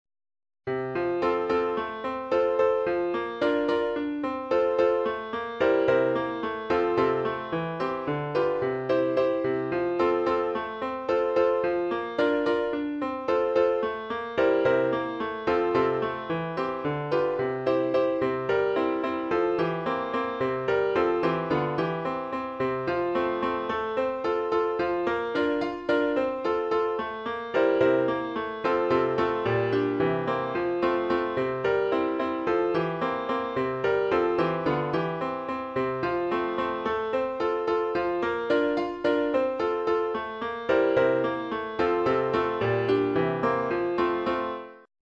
Esempi di musica realizzata con il sistema temperato